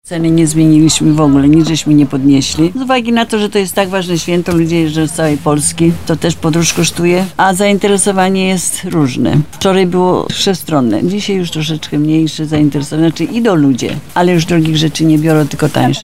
Także przy cmentarzach można ujrzeć więcej stoisk, zapytaliśmy właścicielkę jednego z nich, jak dziś wygląda sytuacja.